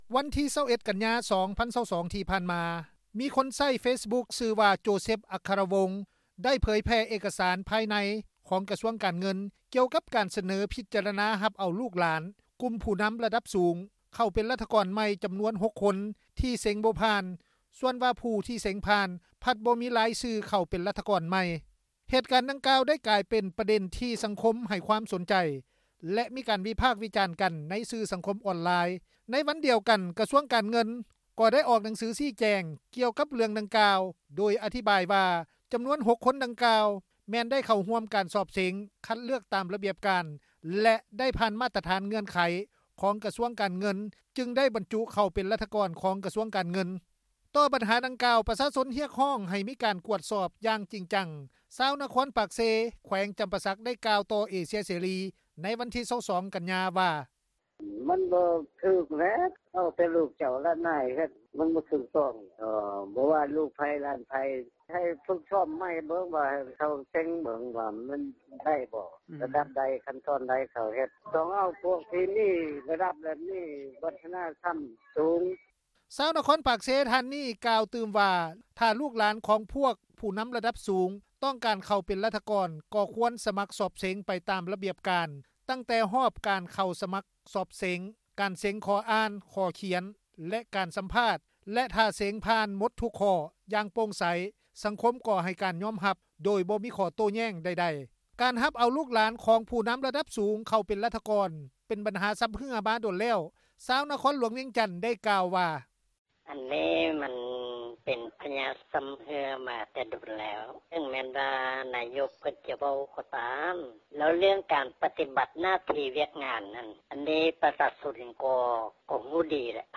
ຊາວນະຄອນປາກເຊ ແຂວງຈຳປາສັກ ໄດ້ກ່າວຕໍ່ວິທຍຸ ເອເຊັຽເສຣີ ໃນວັນທີ 22 ກັນຍາ ວ່າ:
ຊາວໜຸ່ມຄົນນຶ່ງ ຢູ່ແຂວງສວັນນະເຂດ ໄດ້ກ່າວຕໍ່ວິທຍຸ ເອເຊັຽເສຣີ ໃນວັນທີ 22 ກັນຍາ ວ່າ: